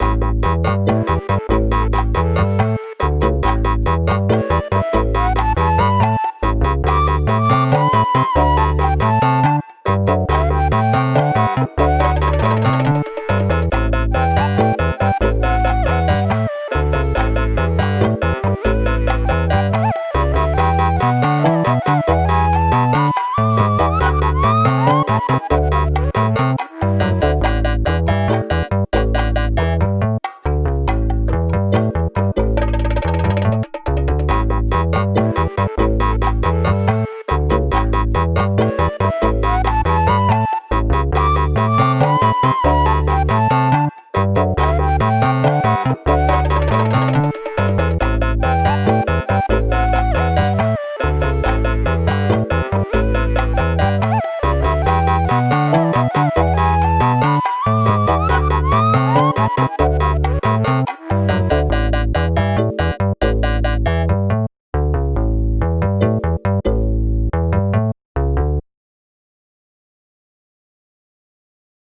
おしゃれ かわいい まったり FREE BGM